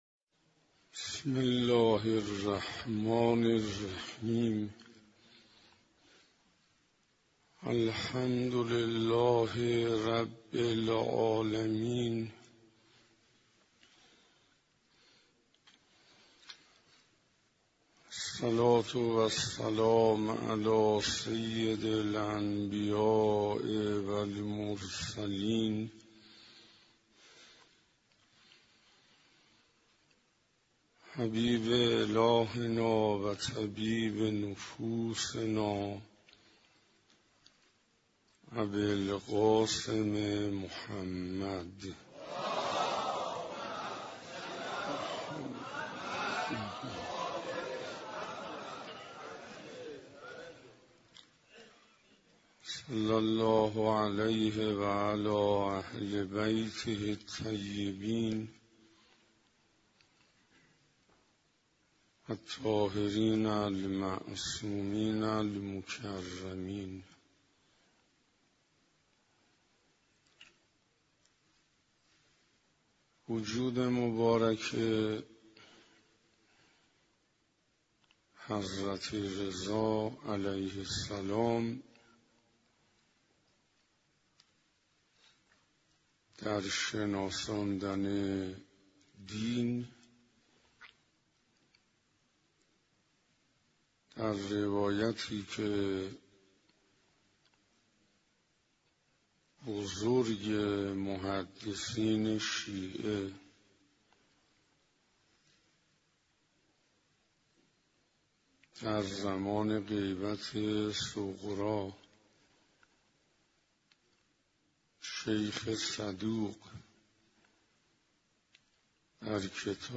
سخنرانی حجت الاسلام والمسلمین انصاریان با موضوع پیامبر رحمت